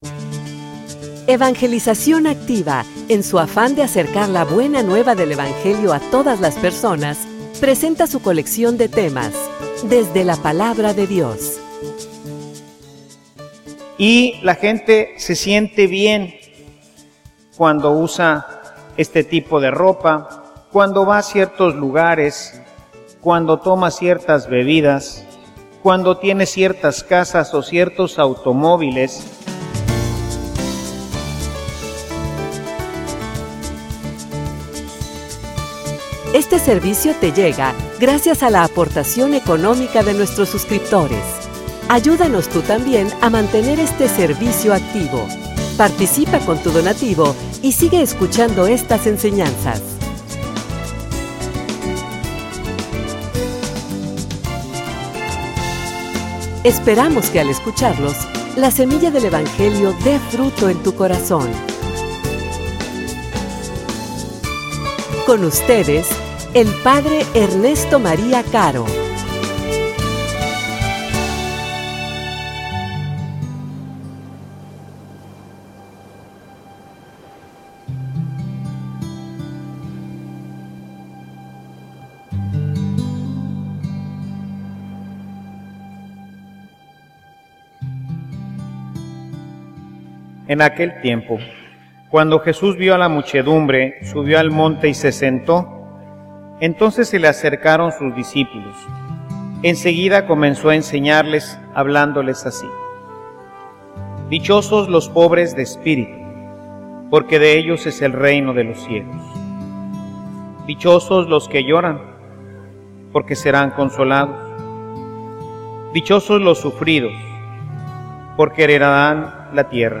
homilia_Quieres_ser_feliz.mp3